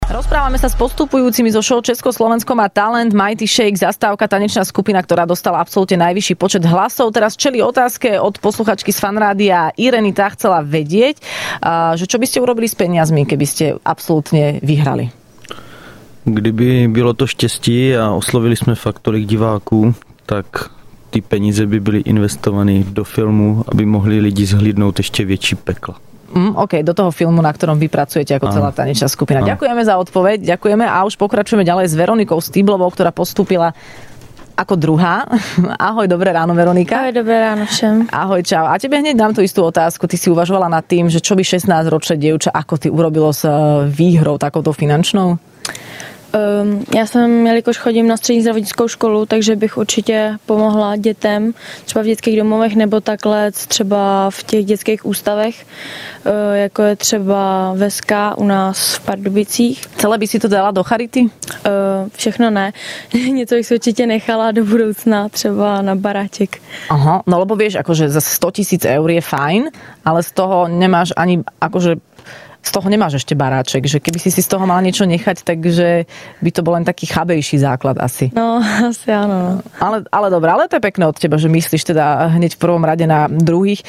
Do Rannej šou prišli ďalší dvaja postupujúci zo šou Česko-Slovensko má talent.